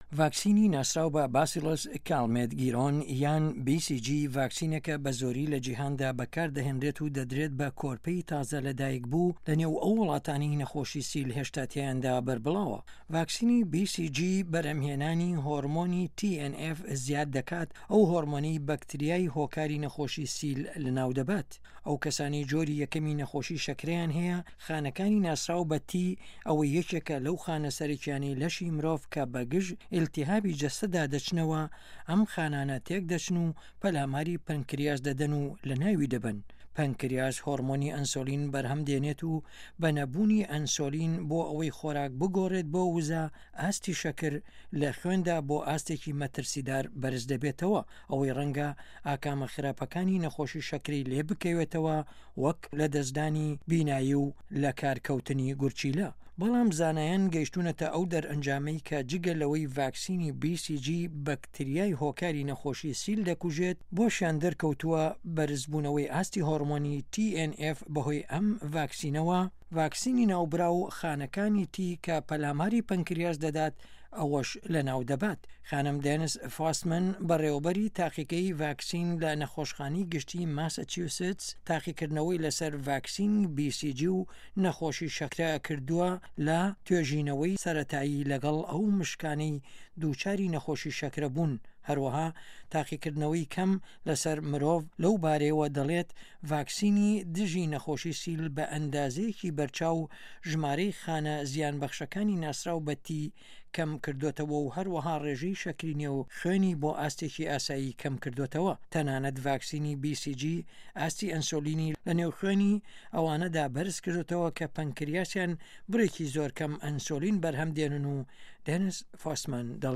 ده‌قی راپۆرته‌که‌- ڤاکسینی بی سی جی